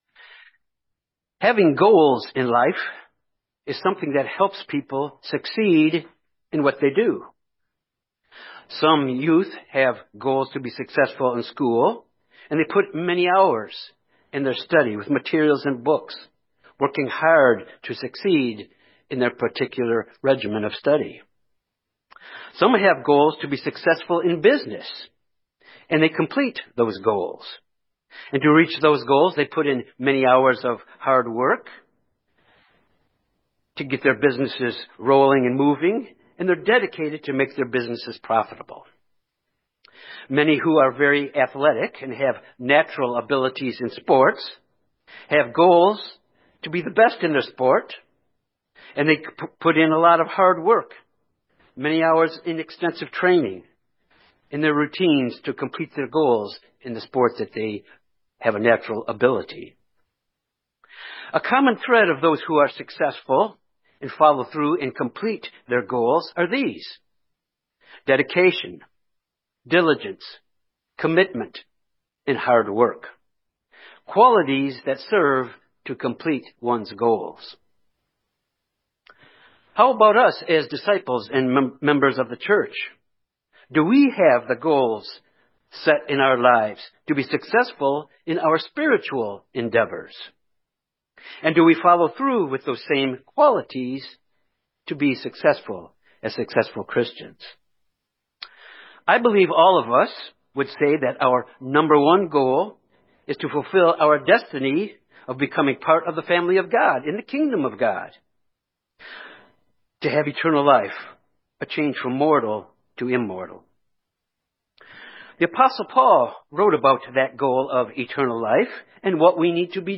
This message examines character qualities, spiritual character qualities, that we need to develop to finish our goal of entering eternal life to be part of God’s spiritual family in the Kingdom of God.